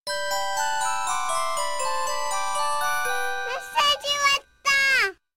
알림음(효과음) + 벨소리
알림음 8_메세지왔떠.ogg